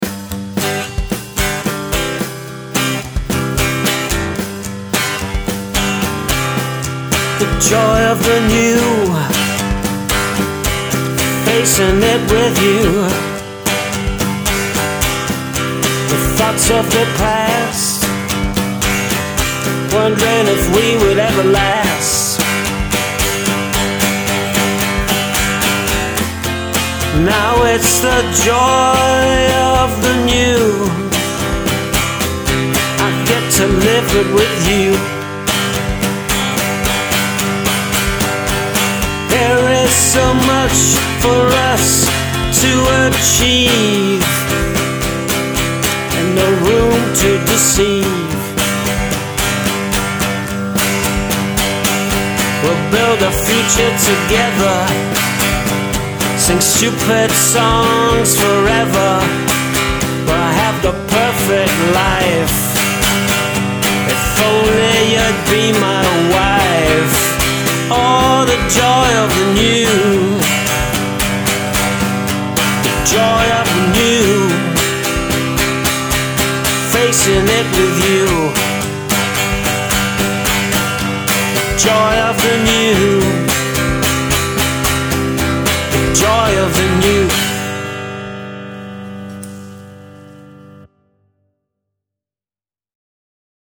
oh yes great intro build up on this one! waiting for power stuff to come in, 2nd stanza I hear "Doors" keys farfisa.
Nice work, I liked the grit in the guitar.